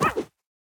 Minecraft Version Minecraft Version 1.21.4 Latest Release | Latest Snapshot 1.21.4 / assets / minecraft / sounds / mob / armadillo / roll3.ogg Compare With Compare With Latest Release | Latest Snapshot